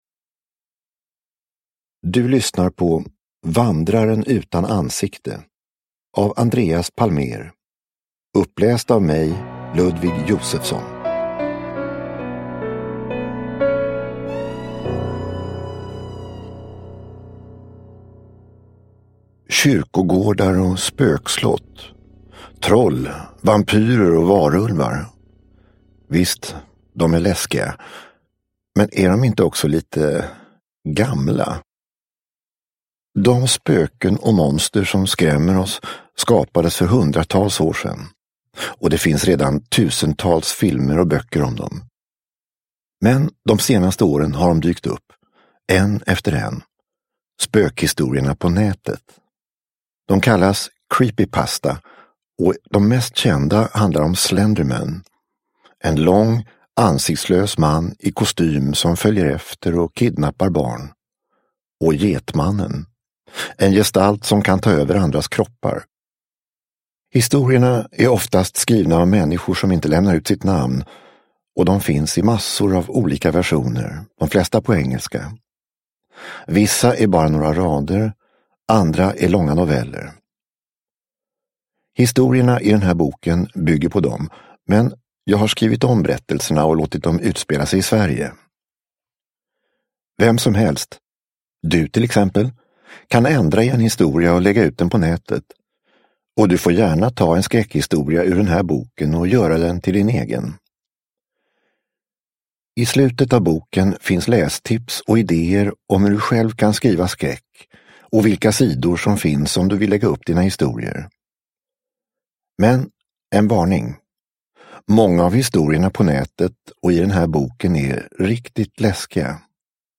Vandraren utan ansikte : skräckhistorier för orädda – Ljudbok – Laddas ner